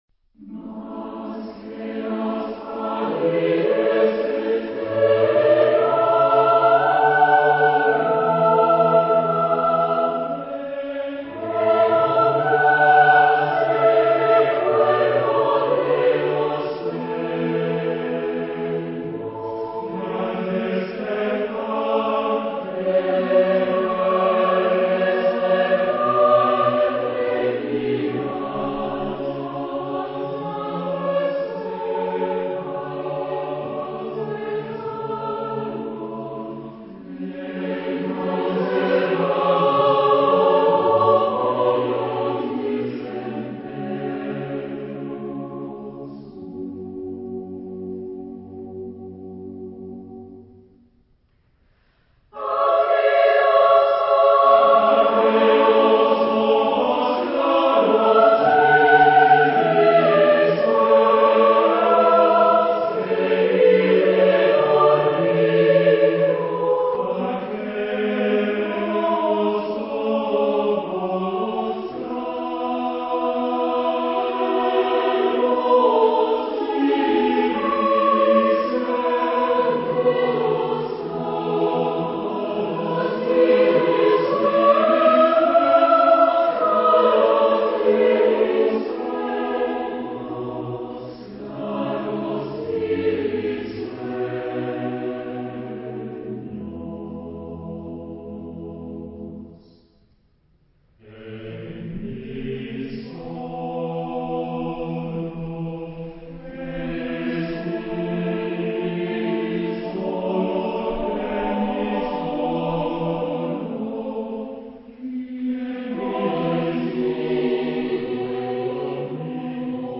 Mood of the piece: supple
Type of Choir: SATB  (4 mixed voices )
Tonality: A major